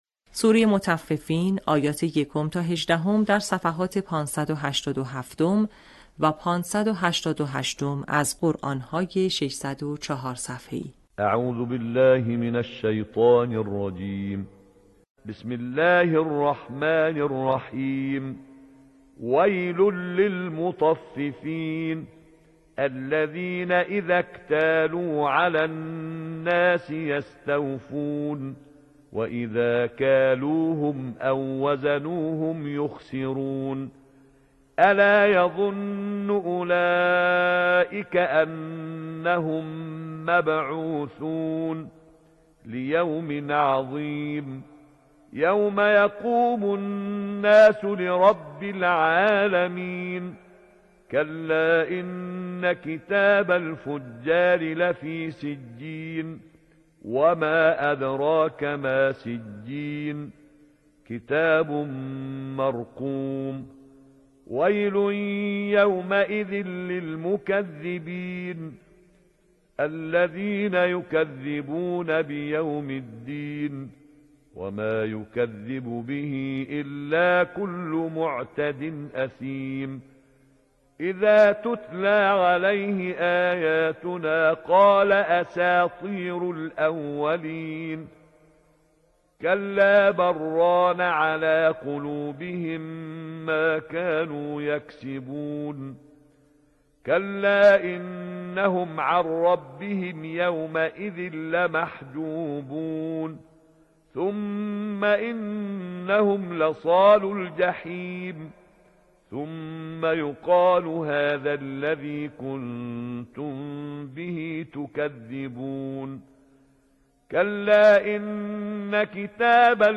صوت | آموزش حفظ جزء ۳۰، آیات ۱ تا ۱۸ سوره مطففین